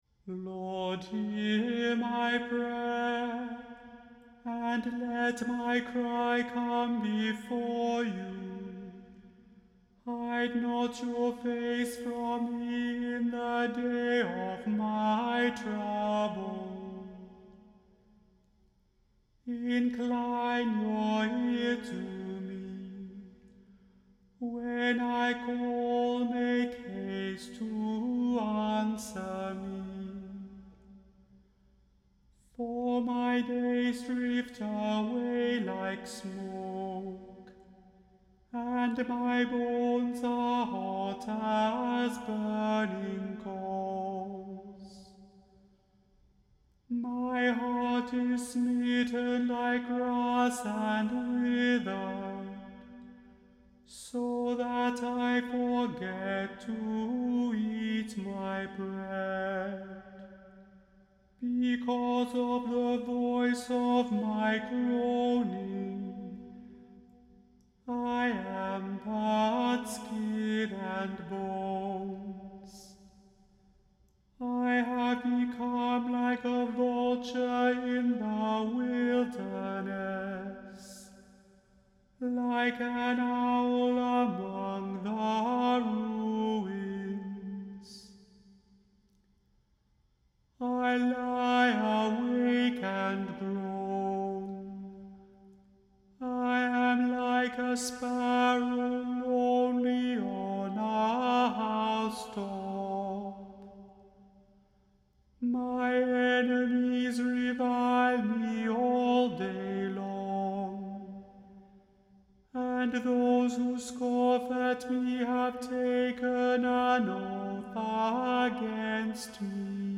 The Chant Project – Chant for Today (March 31) – Psalm 102 vs 1-12